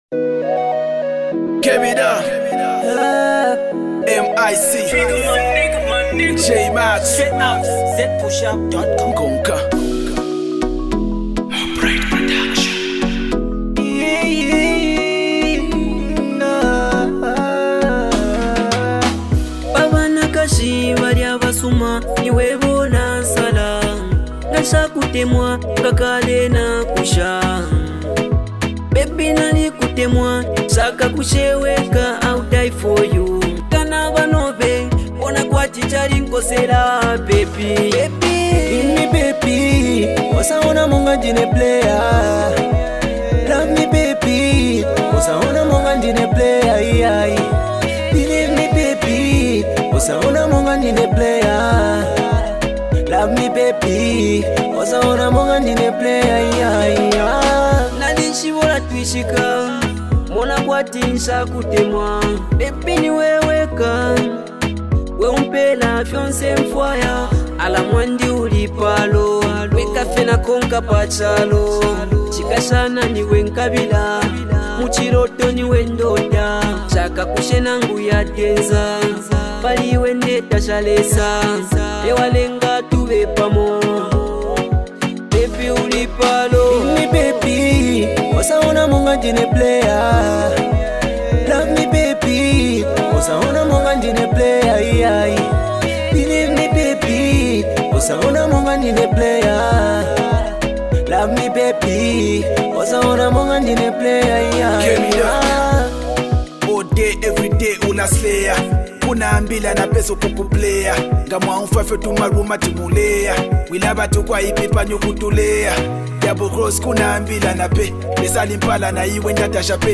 rnb song